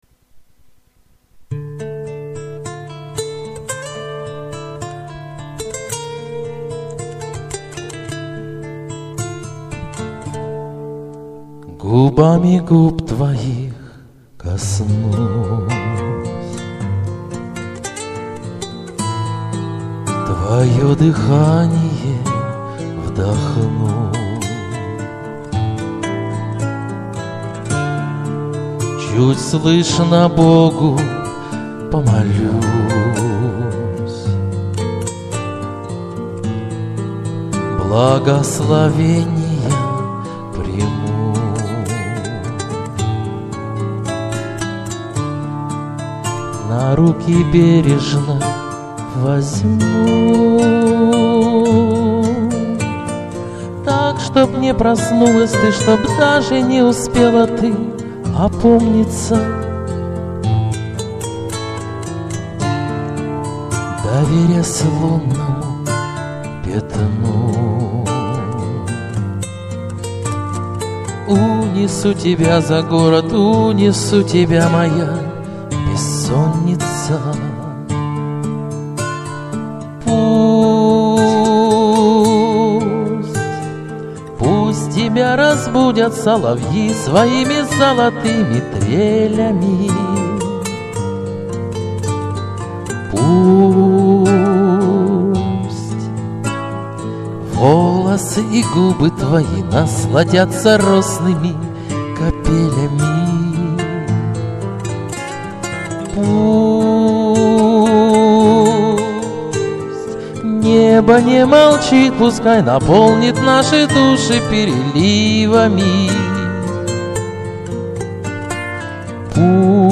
Пока со звуком у меня проблемы...13